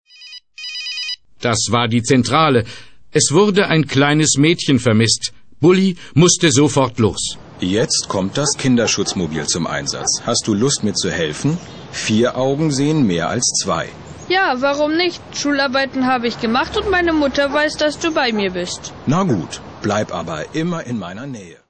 Das Hörbuch "Schutzbär Bulli", CD zum Buch